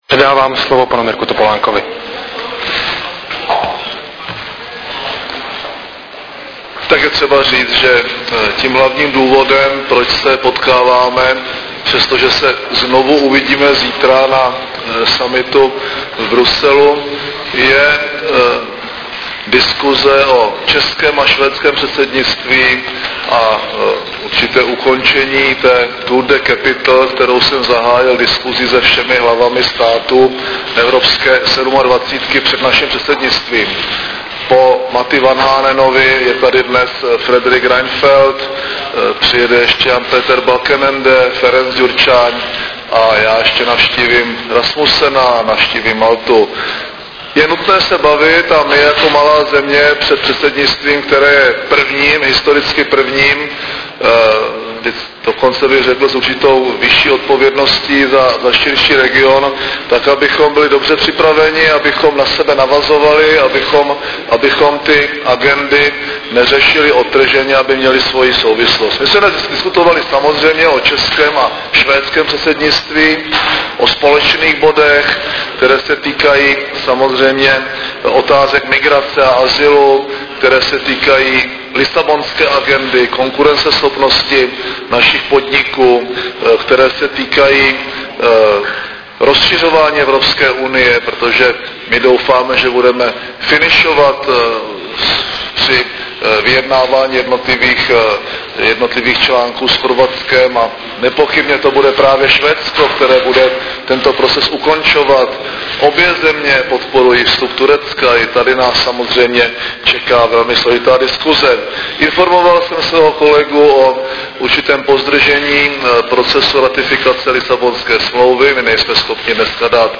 Tisková konference premiéra M. Topolánka švédského premiéra Fredrika Reinfeldta